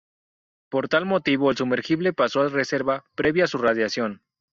/moˈtibo/